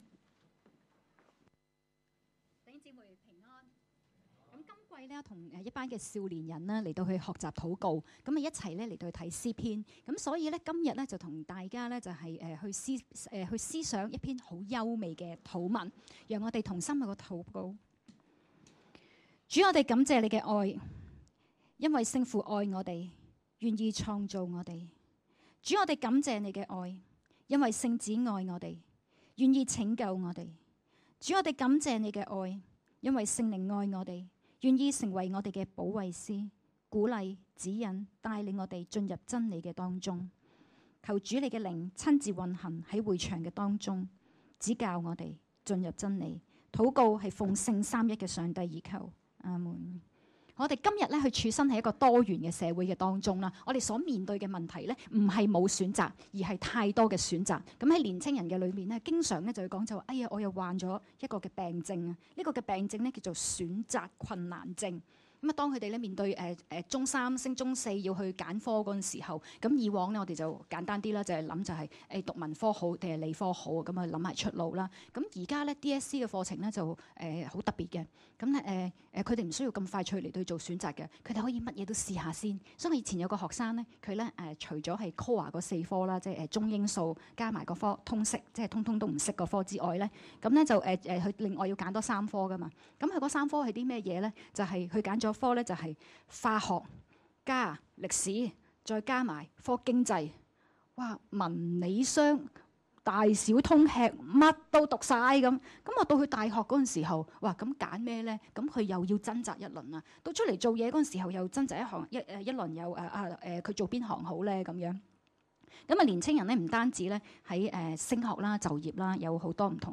2016年6月11日及12日崇拜
講道：逆境中與神同行